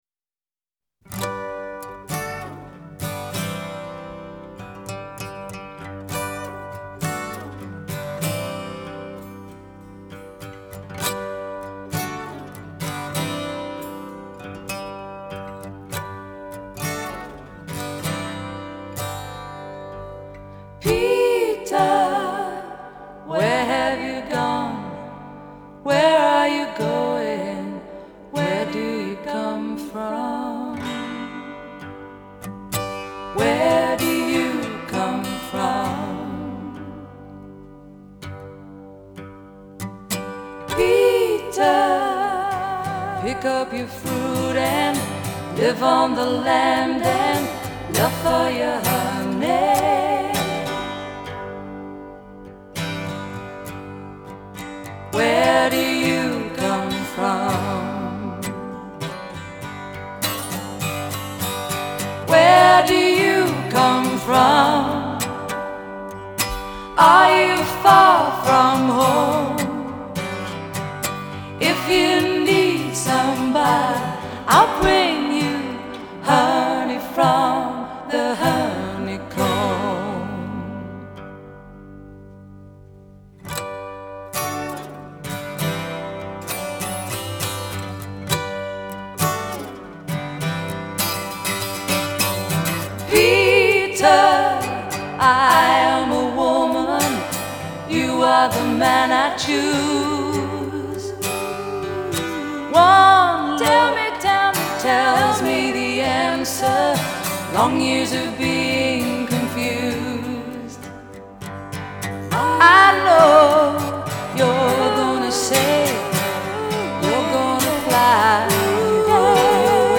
Genre : Soul